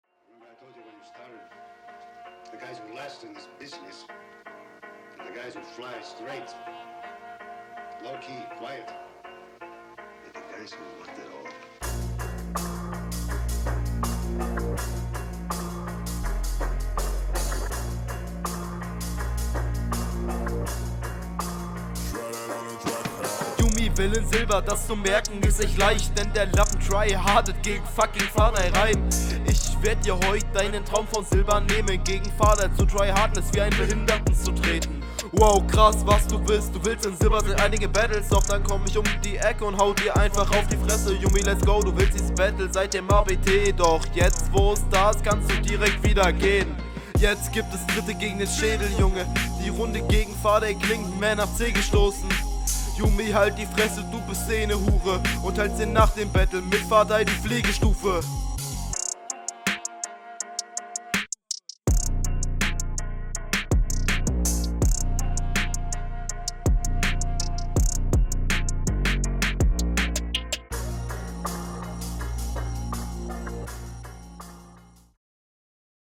Beat ist tatsächlich sehr nice.